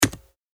キーボード | 無料 BGM・効果音のフリー音源素材 | Springin’ Sound Stock
タイピング-パンタグラフ単3.mp3